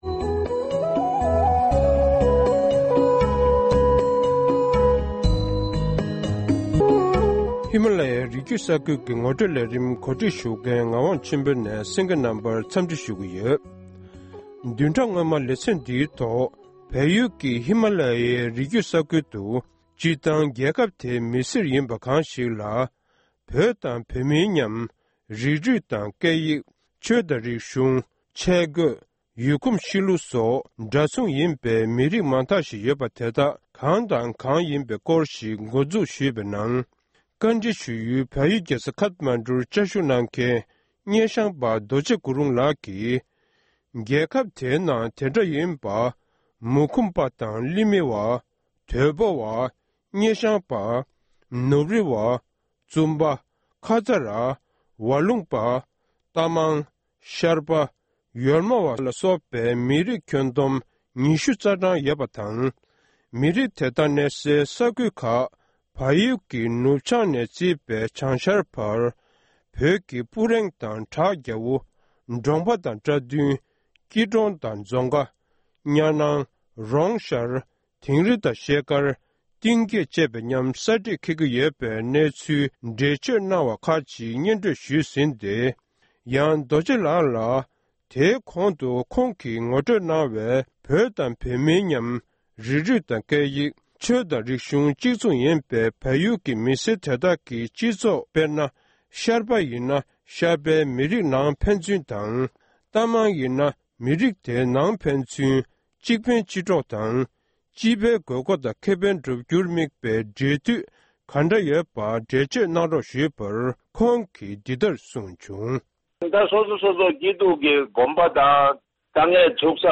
གནས་འདྲི་